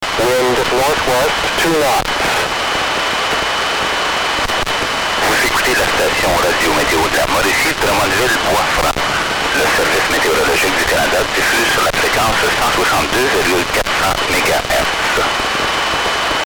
WX DXing (Last update: November 25, 2009)
All received in Southwestern Quebec, Canada.